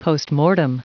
Prononciation du mot postmortem en anglais (fichier audio)